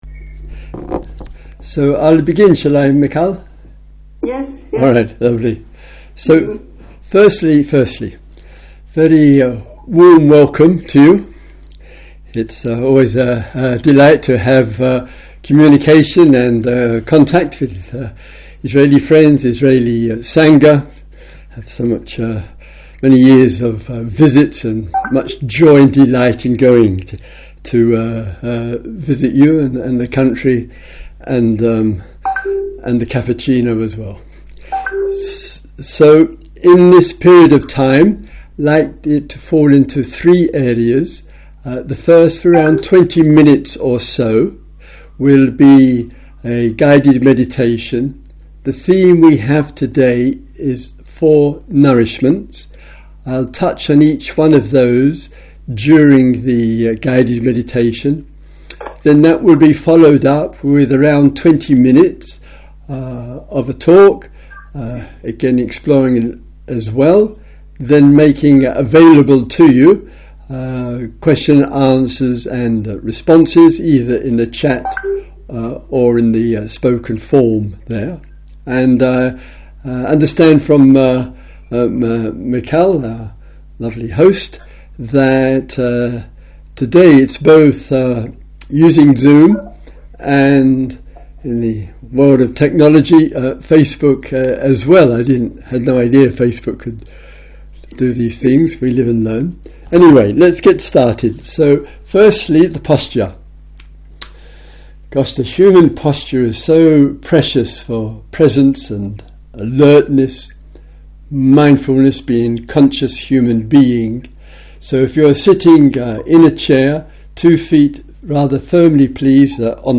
Talk includes a 20 guided meditation on the theme, 20 minute talk and 20 minute Q and A. Personal, family, social and global issues can weigh us down.